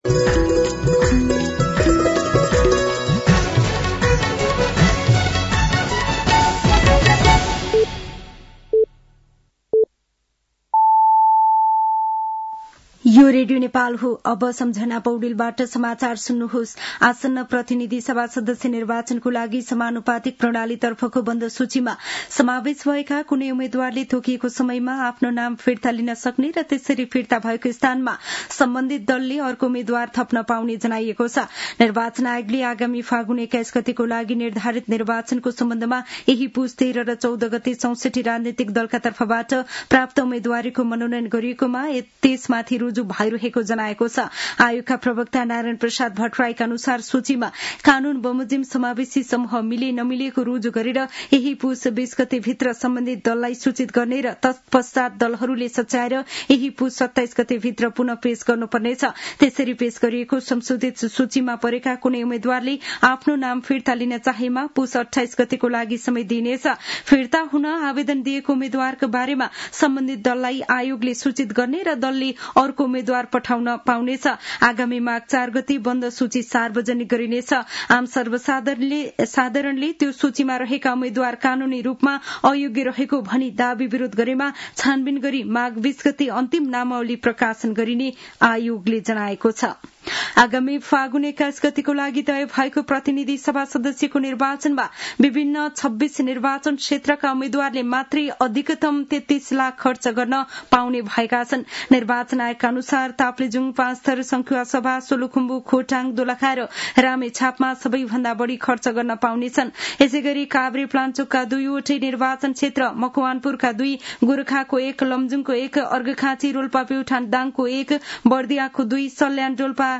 साँझ ५ बजेको नेपाली समाचार : १९ पुष , २०८२